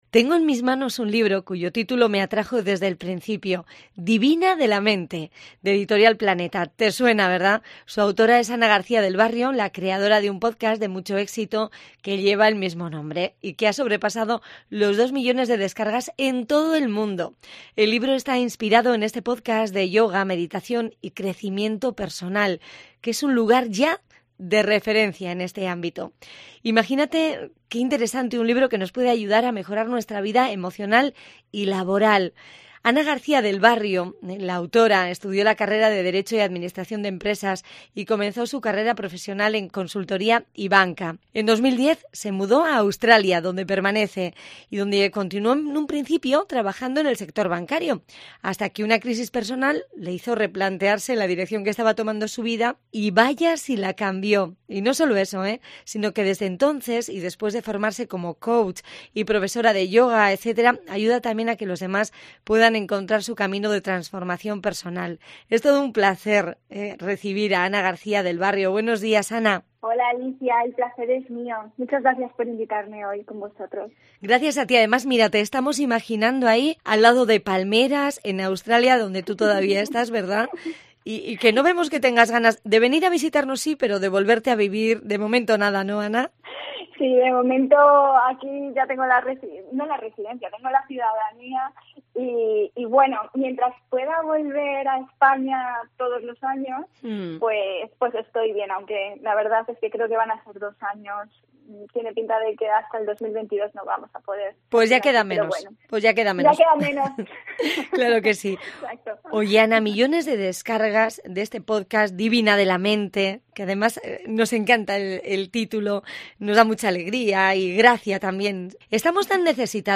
entrevista cope euskadi